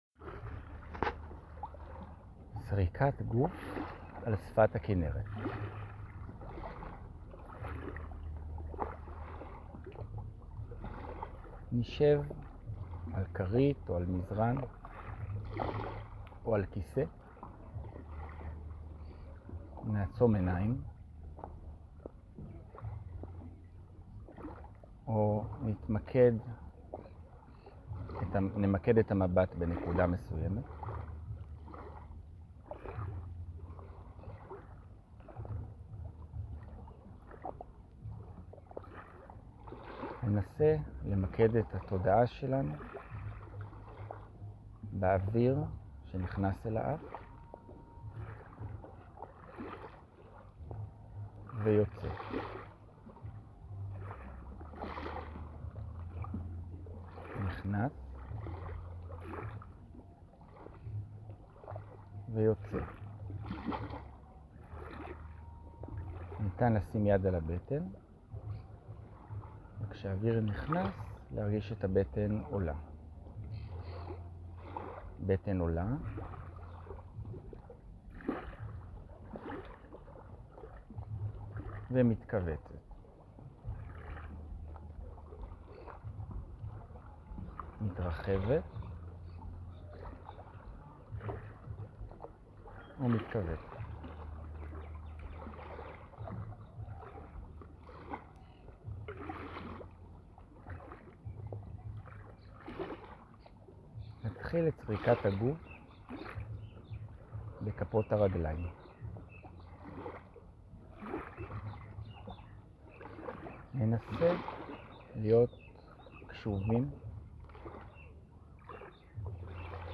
🎧 הקלטות תרגול
body-scan.mp3